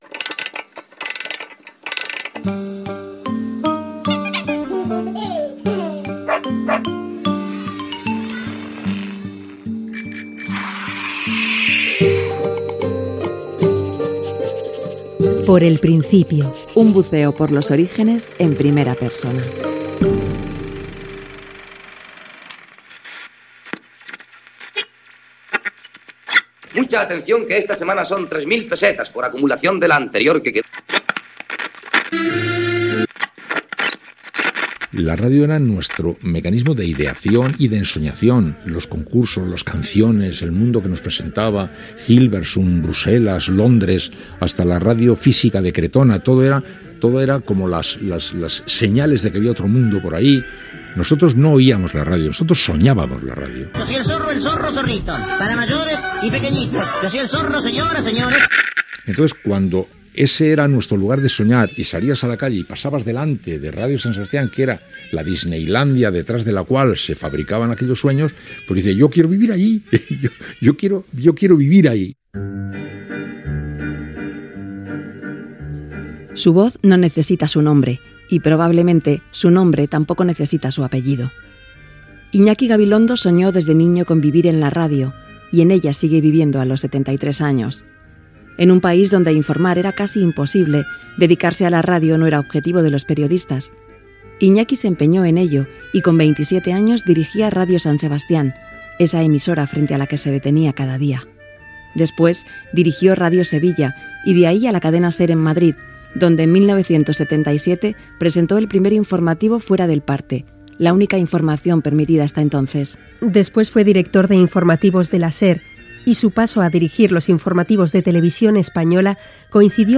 Careta del programa, presentació i entrevista al periodista Iñaki Gabilondo sobre els primers anys de la seva vida i el descobriment de la ràdio.
Entreteniment